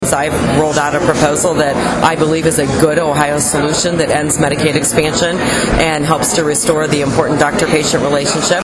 Taylor spoke to us about the issues she believes Republicans are concerned about going into next May’s primary…